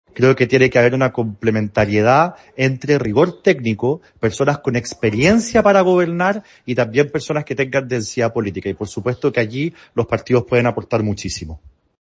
En esa línea, el presidente gremialista, Guillermo Ramírez, aseguró que los partidos están disponibles para ofrecer sus mejores cuadros.